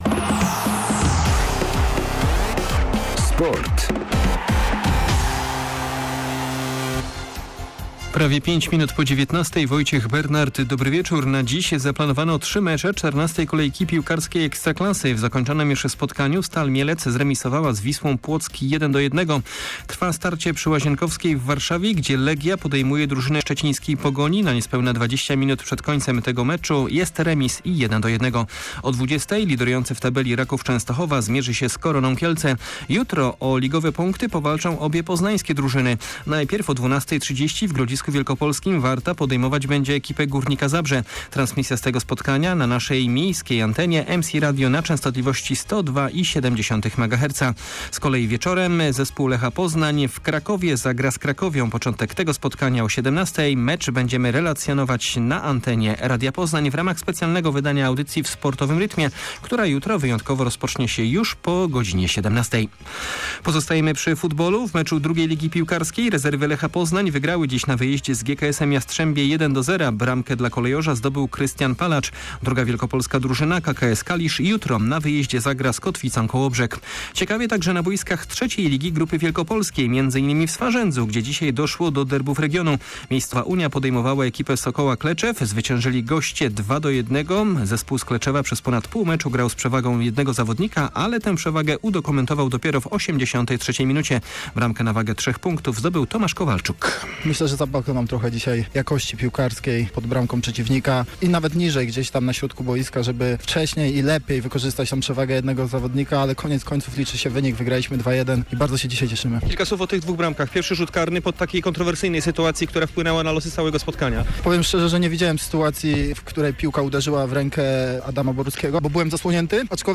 22.10.2022 SERWIS SPORTOWY GODZ. 19:05